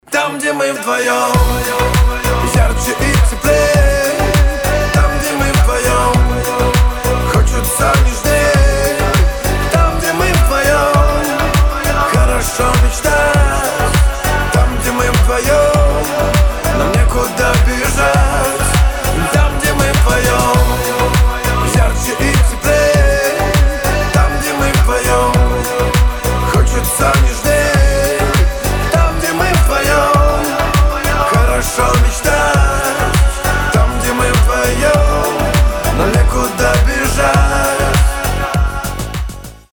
поп
красивый мужской вокал
русская попса
танцевальные